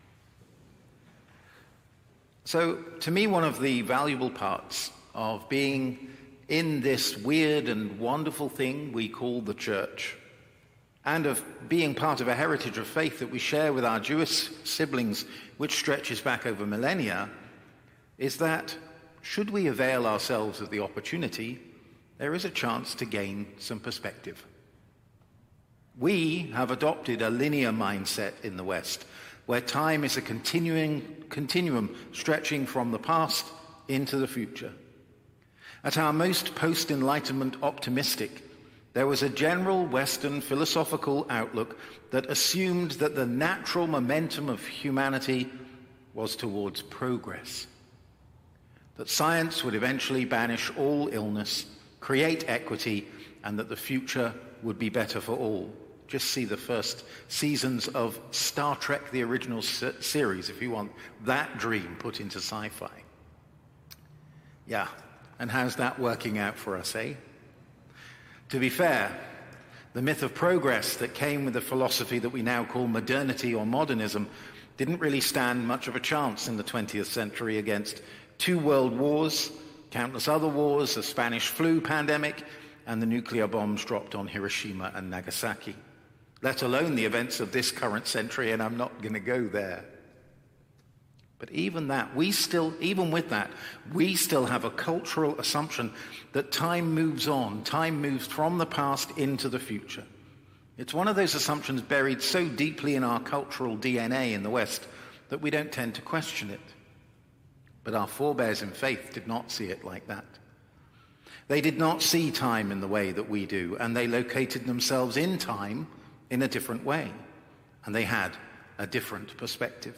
Evensong Reflection